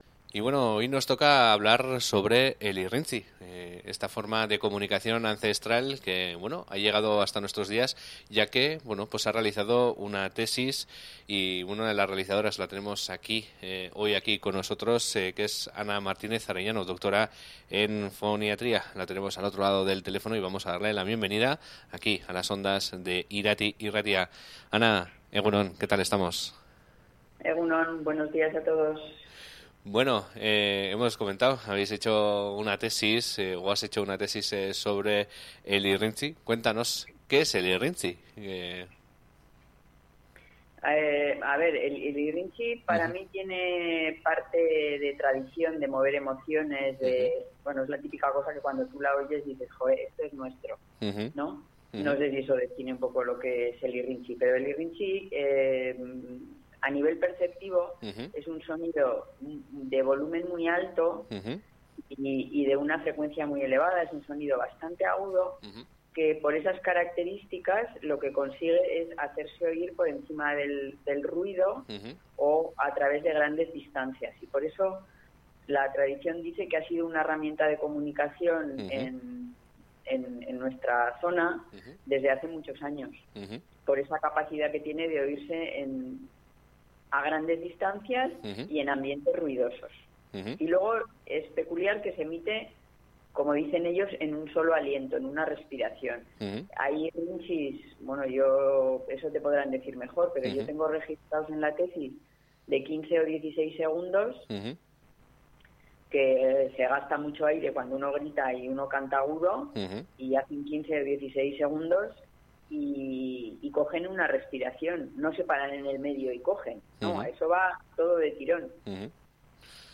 Irrintziaren ikuspegi tekniko bat ematen duen ikerlana dugu zeina 5 urtez egon den ontzen. Hainbat tokitako irrintzilariekin batera eginiko lana da eta datu oso esanguratsuak ematen dituena, baita ahotsaren trataerarako ere. Berarekin solastu gara gaurkoan honen inguruan.